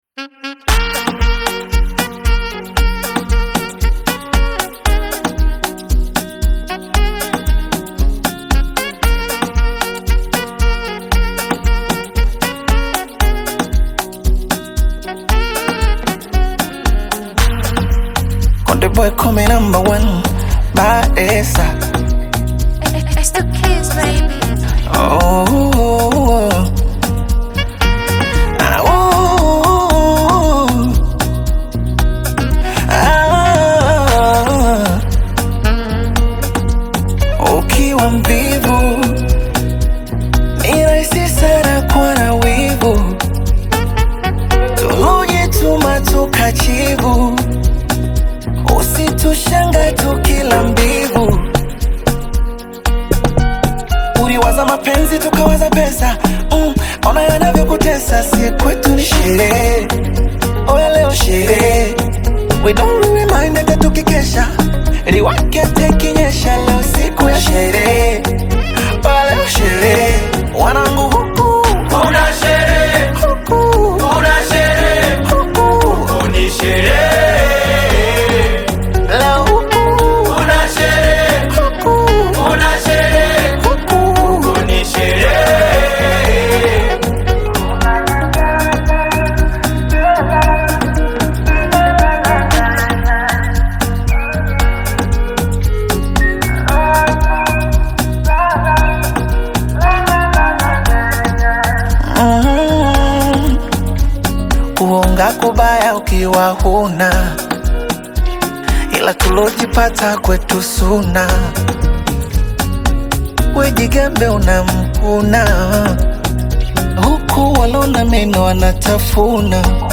blends various musical styles